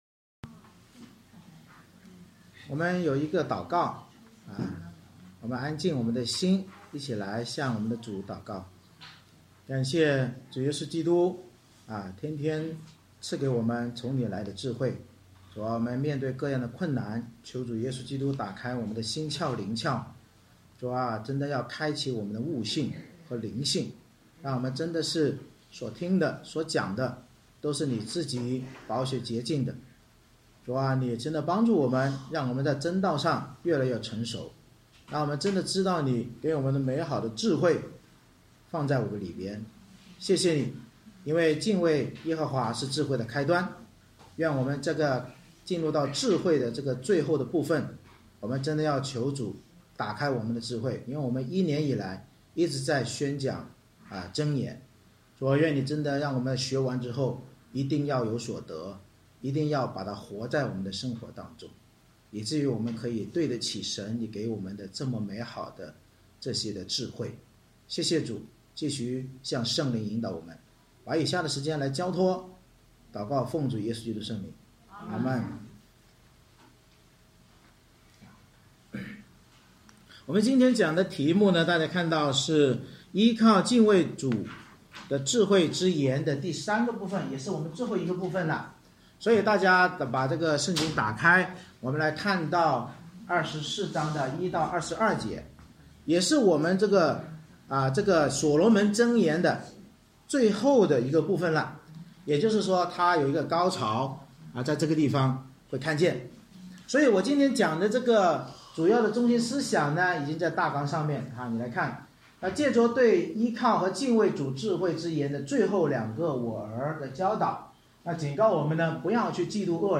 箴言24：1-22 Service Type: 主日崇拜 借着对依靠与敬畏主的智慧之言中最后两个“我儿”的教导，警告我们不要嫉妒恶人而要施行公义才能成为敬畏主基督与统治者的智慧儿女。